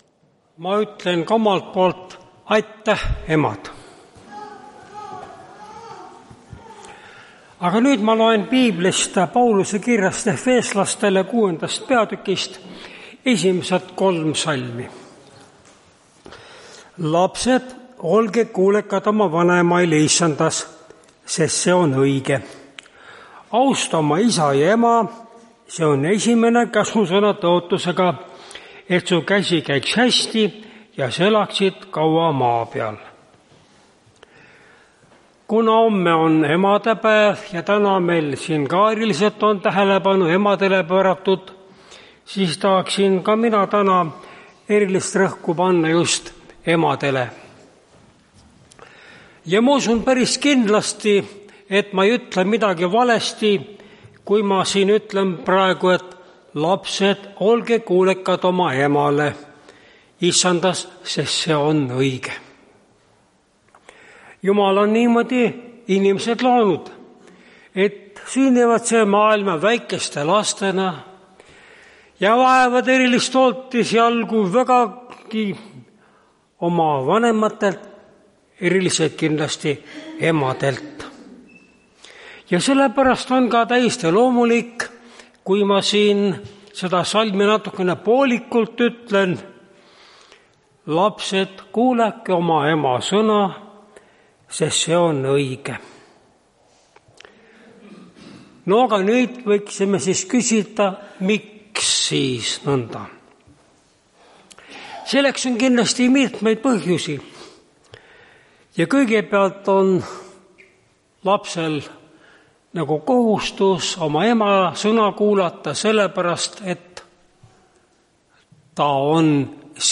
Tartu adventkoguduse 11.05.2024 hommikuse teenistuse jutluse helisalvestis.
Jutlused